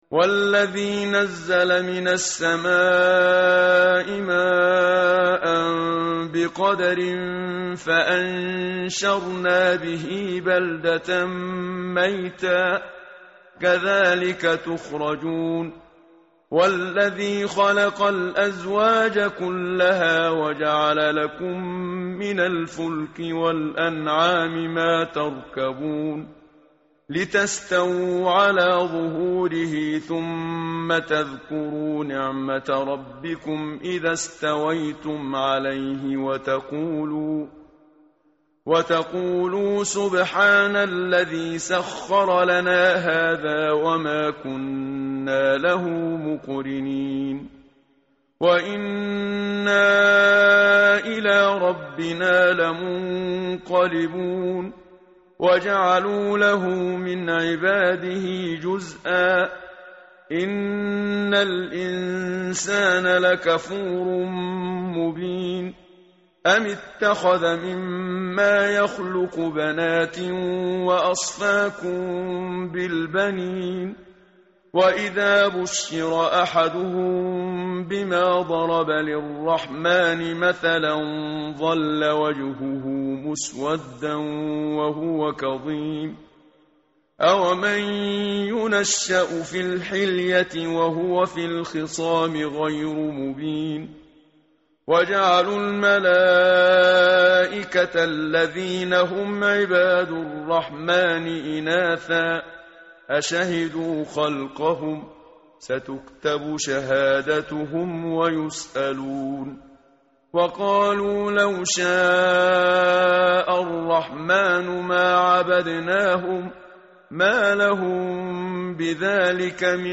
tartil_menshavi_page_490.mp3